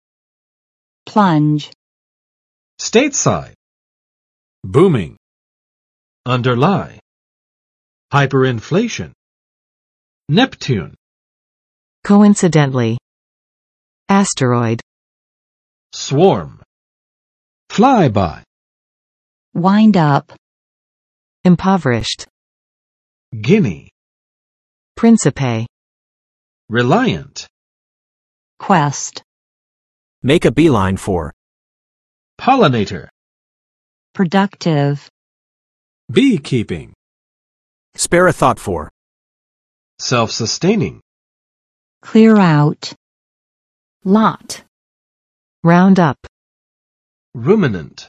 [plʌndʒ] n. 猛跌，骤降